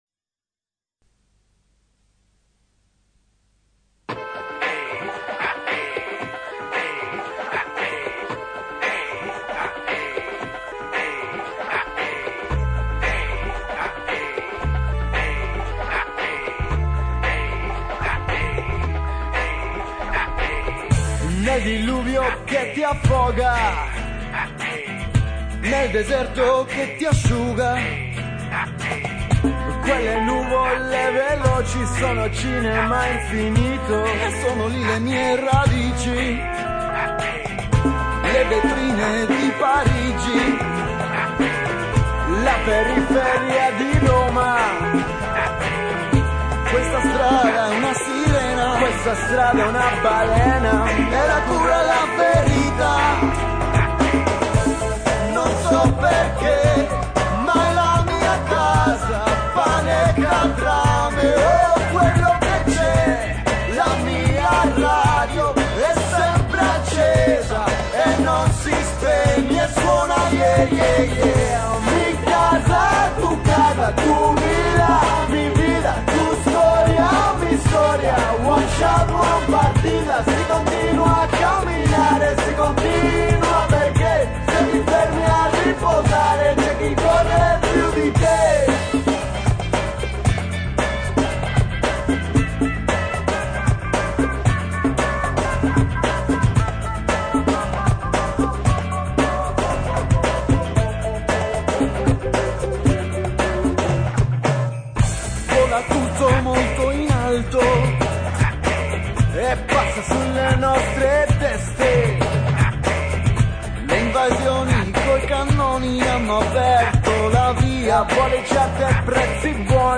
Gesang, Gitarre, Cajon, Djembe, Tarbuca
Congas, Cajon, Percussions, Birimbao
Schlagzeug, Djembe, Cajon, Percussions
Bass